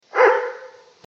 Dog Barking #3 | TLIU Studios
Category: Animal Mood: Alerting Editor's Choice